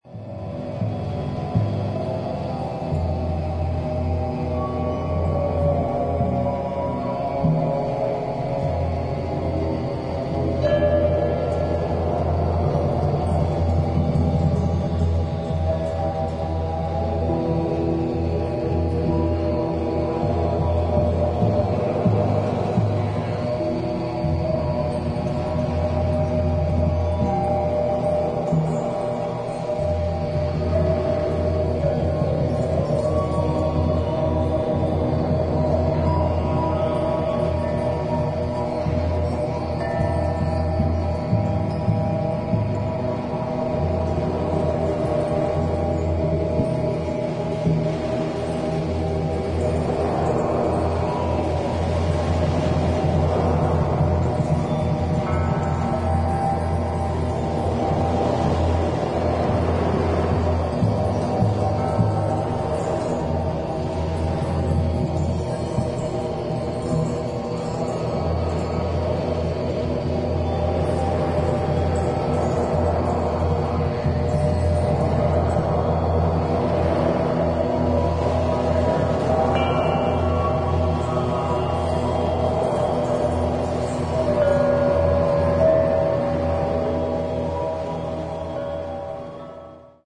現代音楽やフリー・ジャズ、アヴァンギャルドな雰囲気も併せ持つ